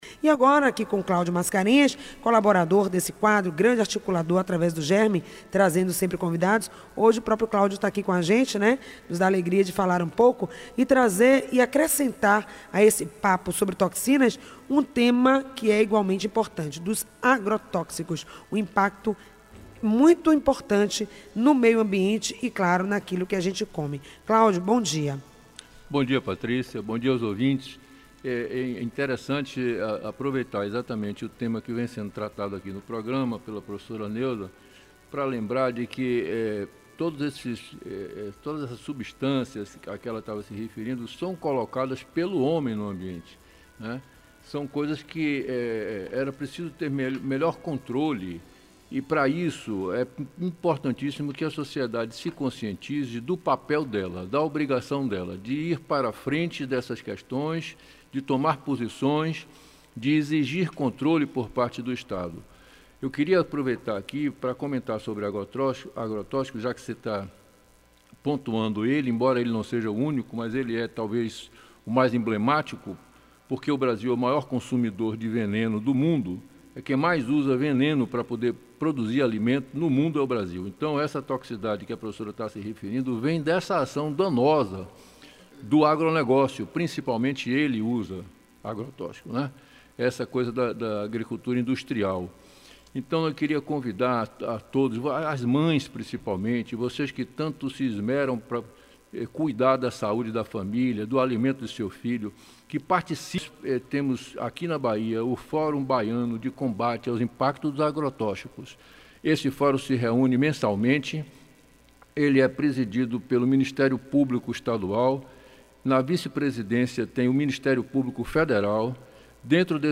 comentário